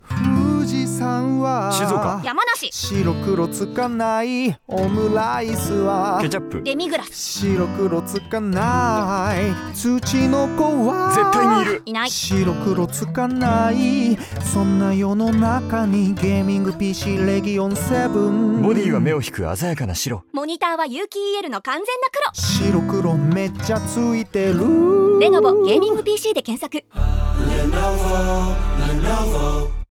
音声CM・広告クリエイティブ事例